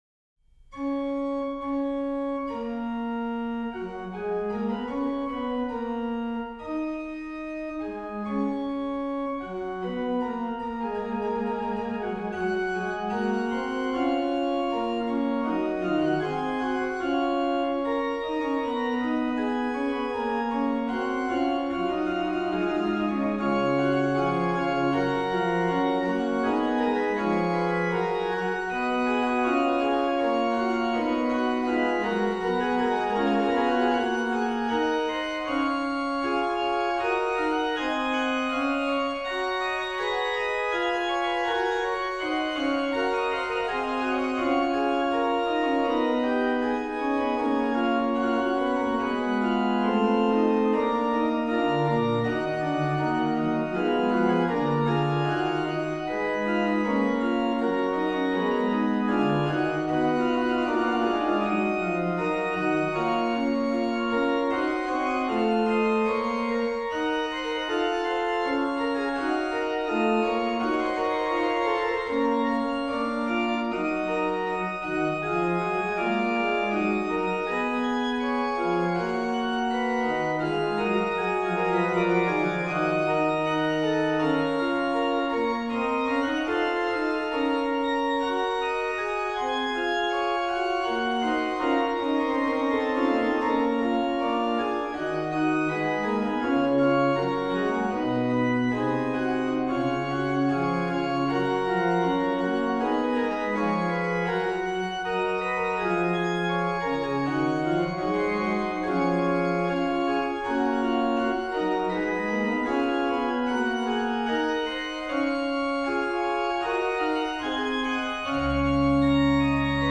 Registration   MAN: Pr8, Oct4, Oct2
PED: Sub16, Oct8, Oct4